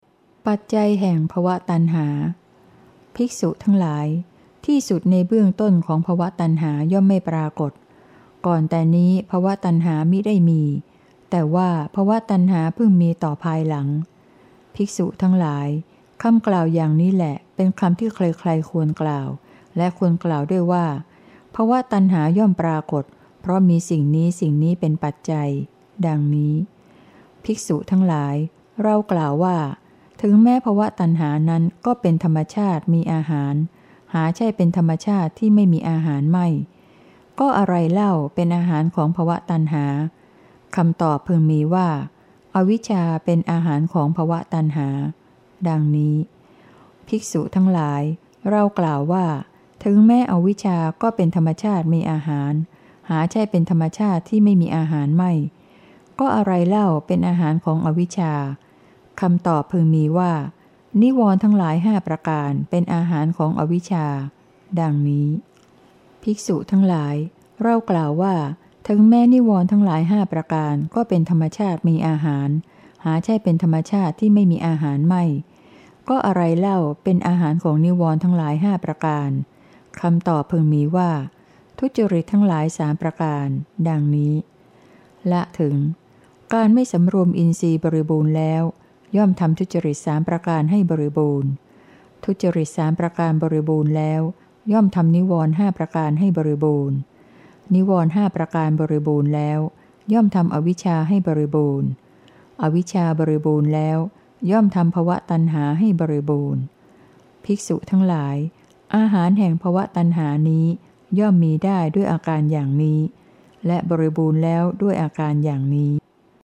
เสียงอ่าน